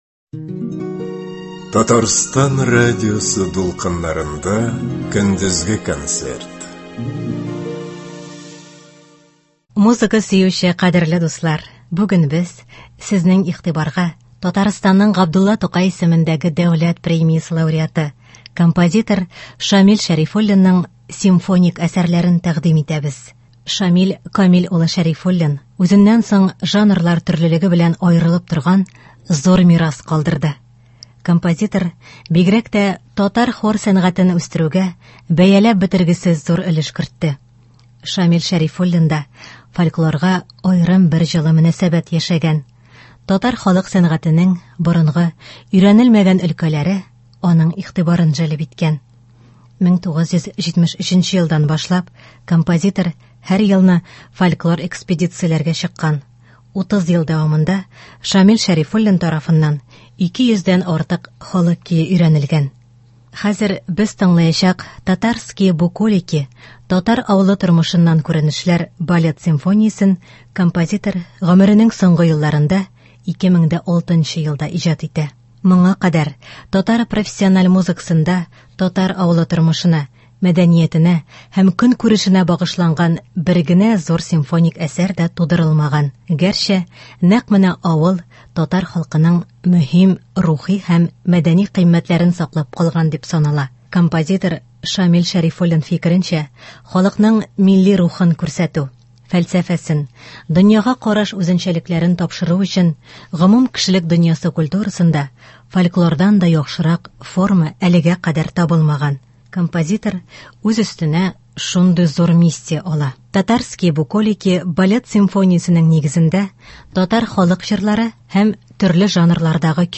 Көндезге концерт.
симфоник әсәрләрен